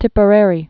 (tĭpə-rârē)